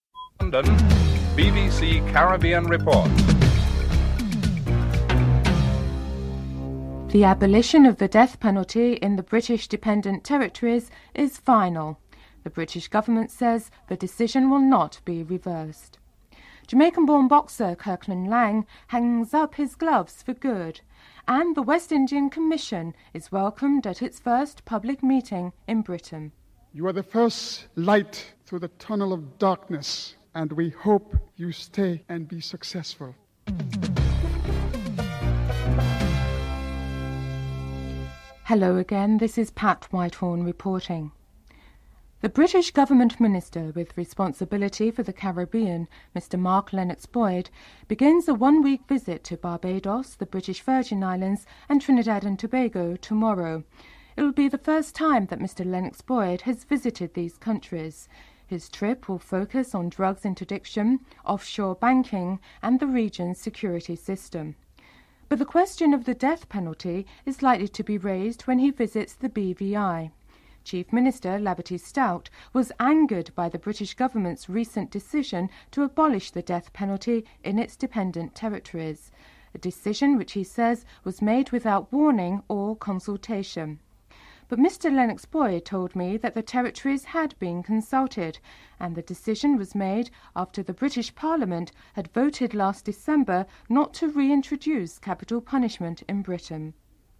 The West Indian Commission in its efforts to continuing the process of consultations, held its first public meeting in Central London with West Indians living in Britain where approximately 400 persons attended. After Sir Shridath Ramphal gave his opening remarks, contributions were taken from the floor.
Previous BBC clip of Laing is played as he explains why he never matured as a boxer (09:20- 13:17)